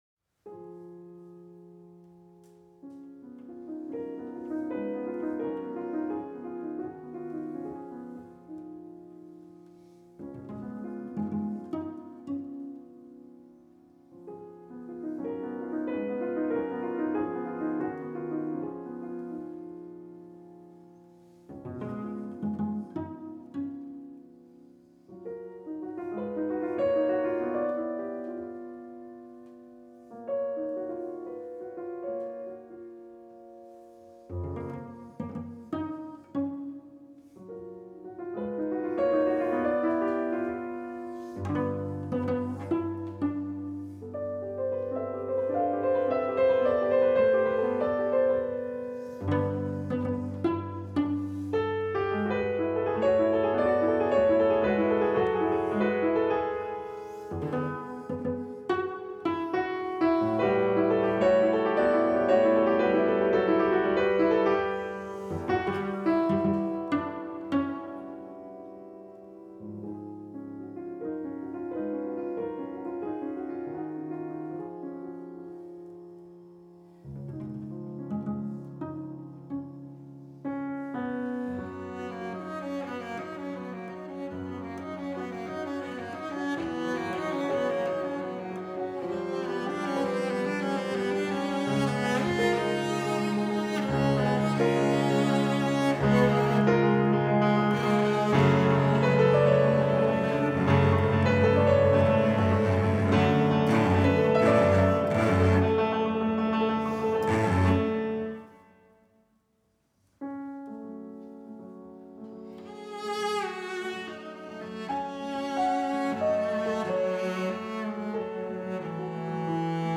Leoš Janáček [1854-1928] Pohádka [1913] Con moto Con moto Allegro The story of Tsar Berendey, his son the Tsarevich Ivan, the intrigues of Kastchey the Immortal and the wisdom of Princess Maria, Kastchey’s daughter.
Venue: Bantry House
Ergodos Instrumentation: vc, pf Instrumentation Category:Duo Artists
cello
piano